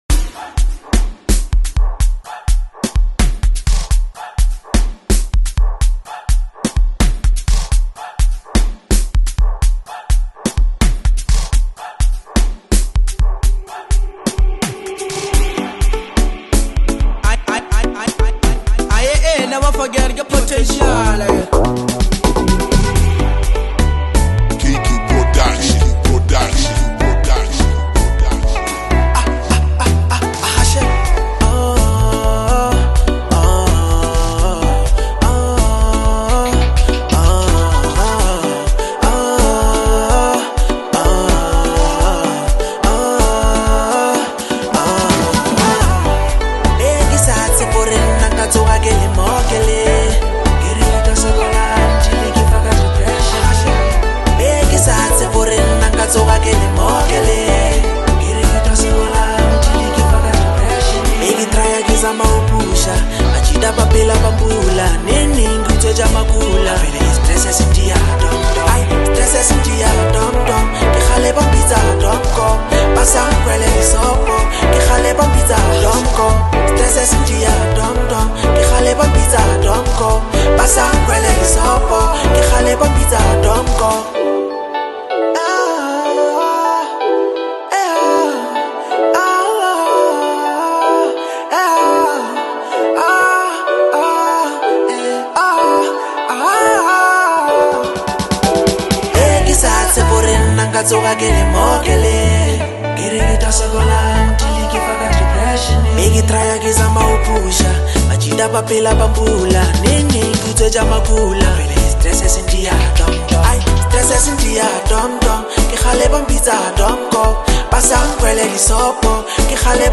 lekompo-house cut
Lekompo